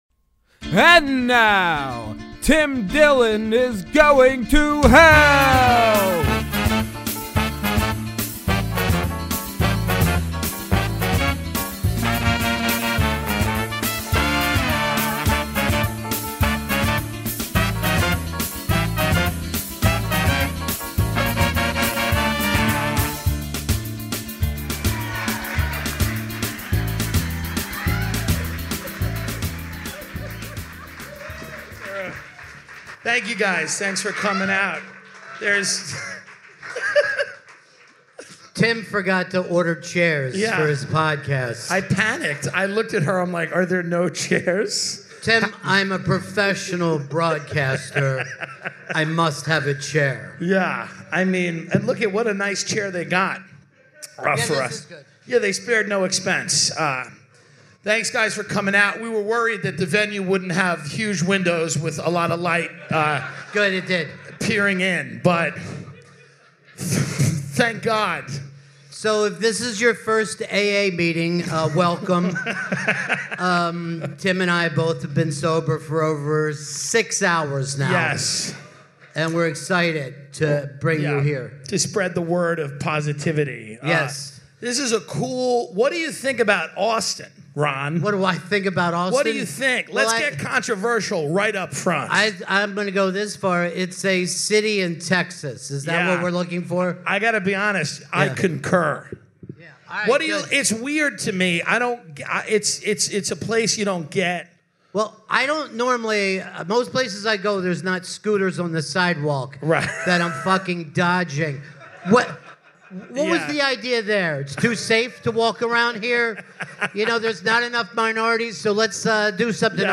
This week's episode was recorded LIVE from Moontower Comedy Festival with the legend Ron Bennington.